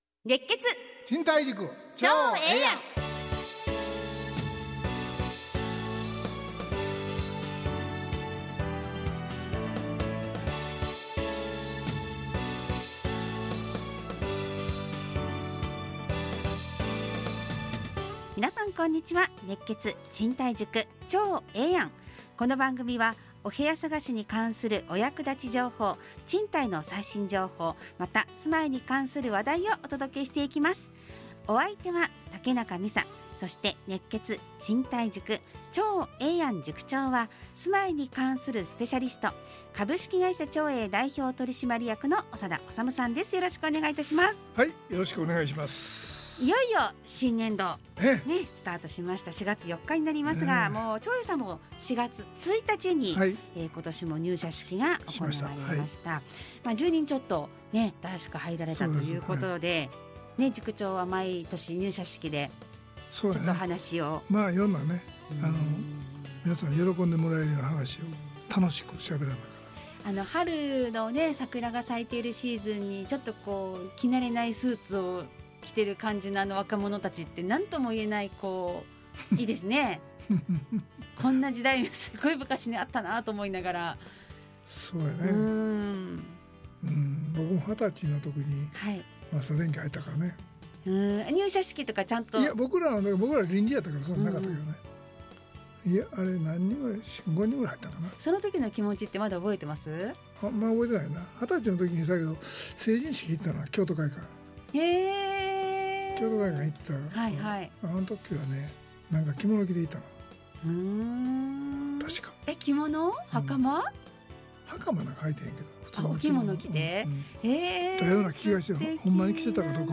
ラジオ放送 2025-04-04 熱血！賃貸塾ちょうええやん【2025.4.4放送】 オープニング： 4月 新年度スタート、長栄入社式、新社会人懐かしい、令和の新人は？